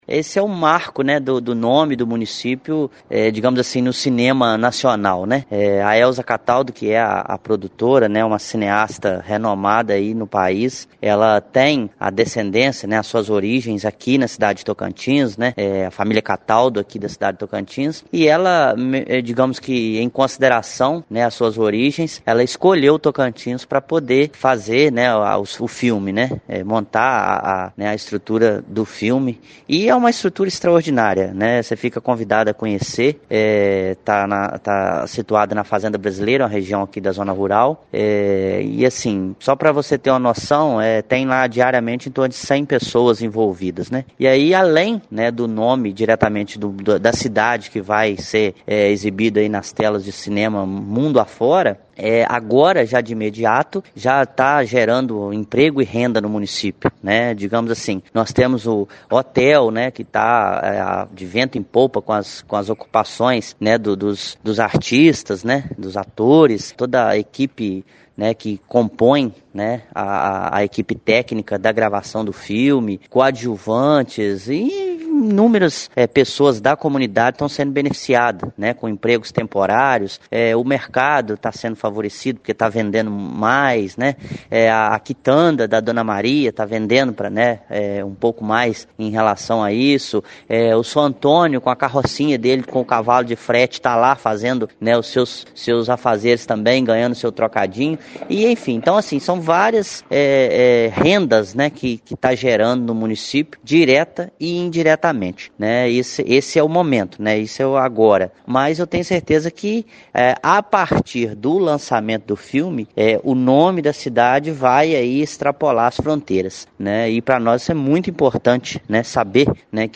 Sonora Prefeito de Tocantins – MG